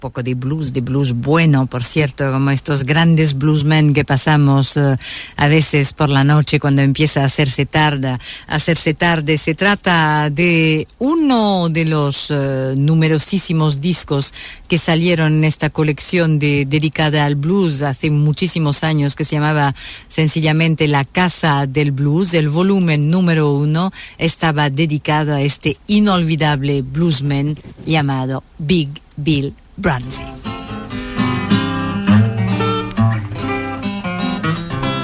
Presentació d'un tema de blues.
Musical